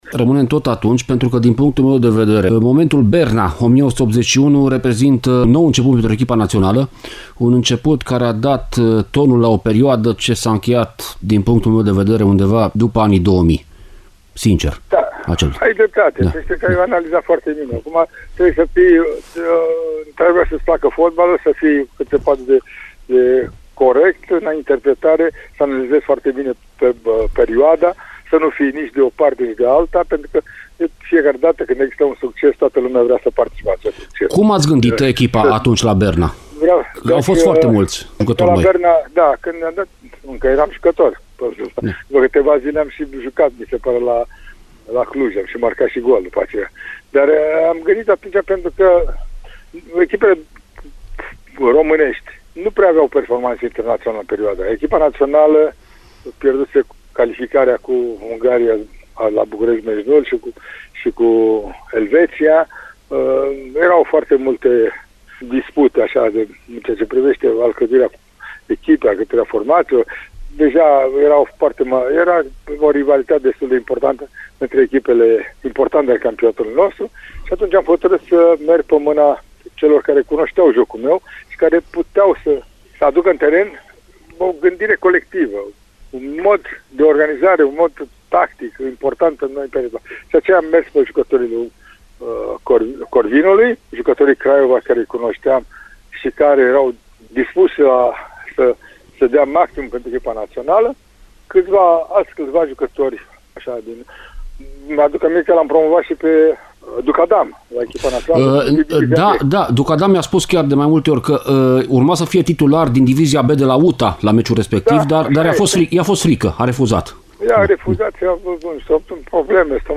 Al treilea episod al vastului interviu